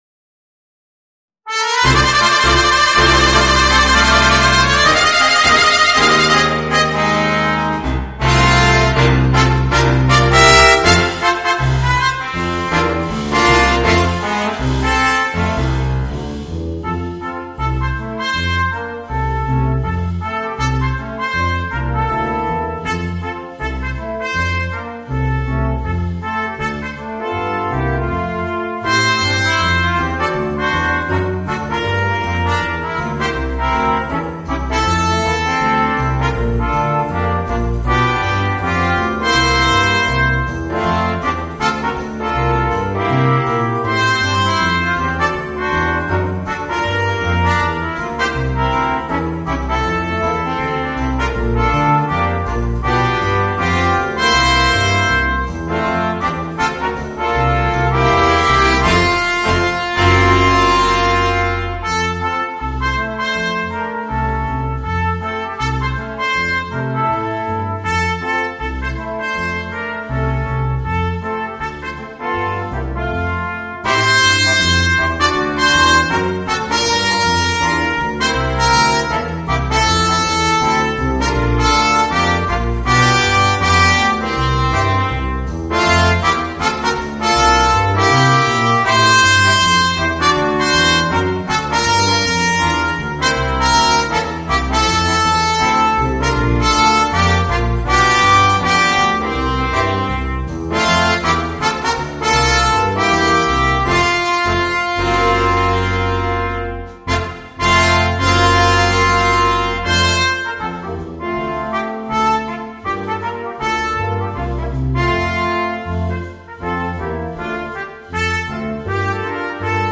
Voicing: 2 Cornets, 2 Euphoniums